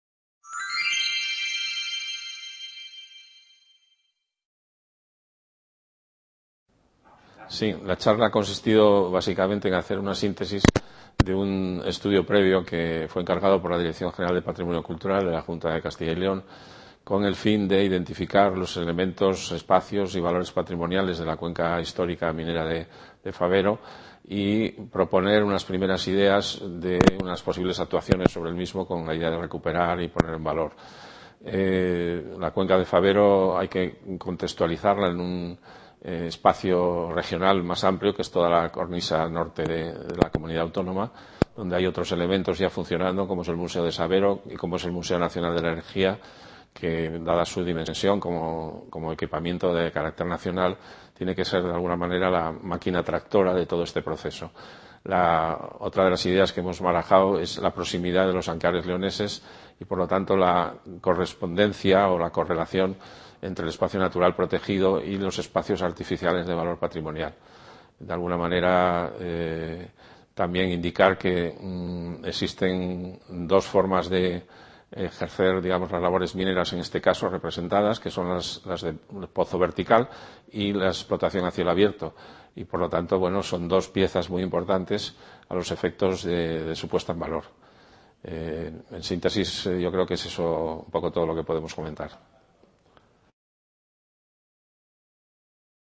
Video clase publico Licencia Propietaria